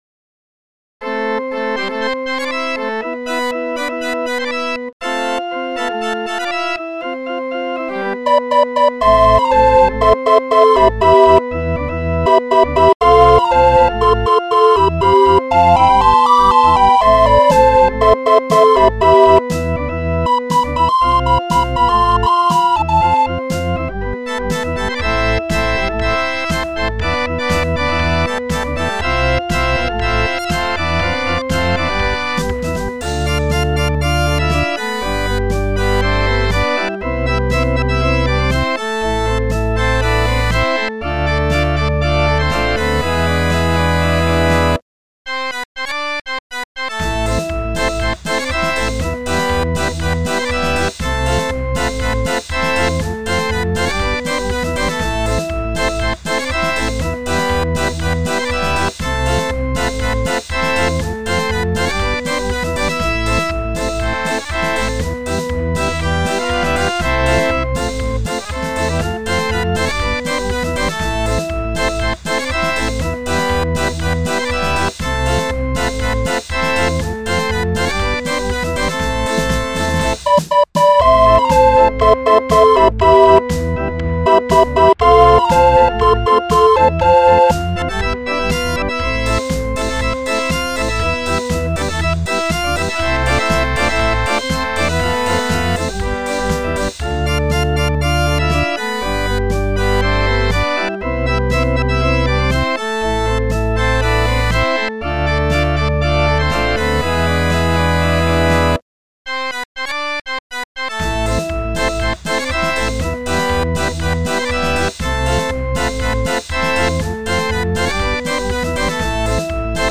Skala: 52 Toets